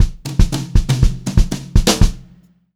120HRFILL1-L.wav